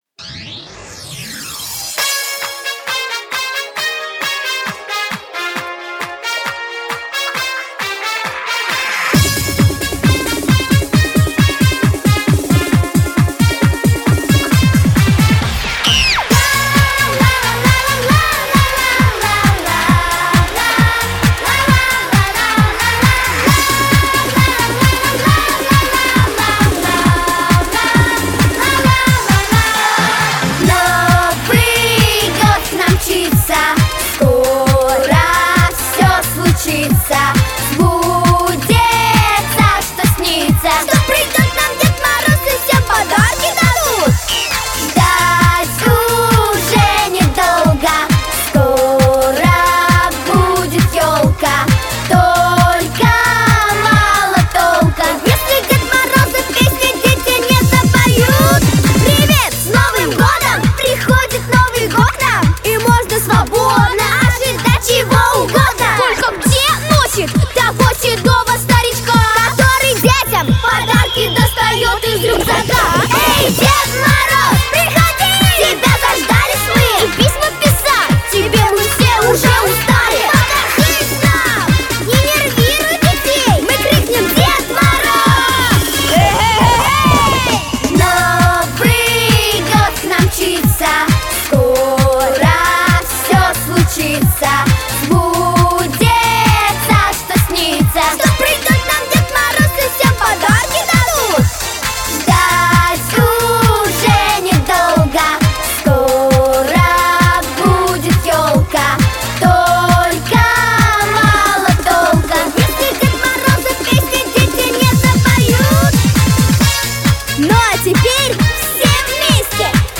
• Категория: Детские песни
cover